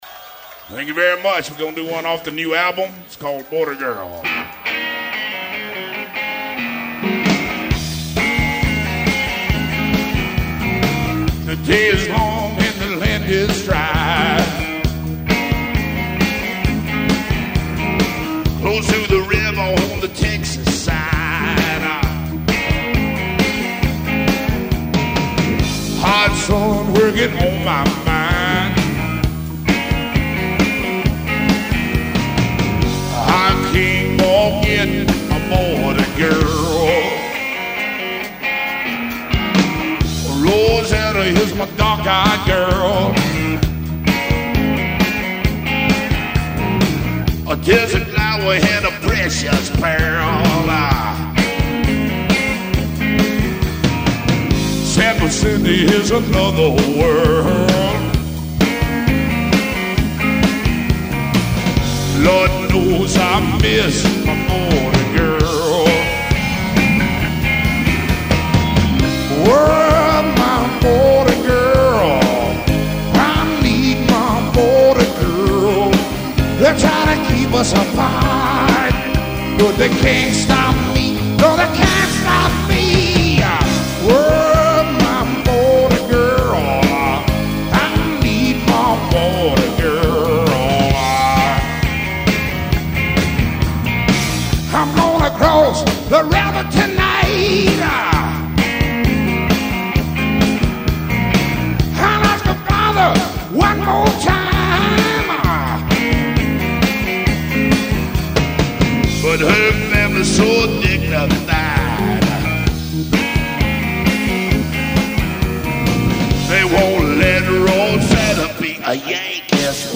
rhythm’n’blues
distinctive husky growl
Don’t let that gruff voice fool you.
swamp rocker
Mixed live to air, no overdubs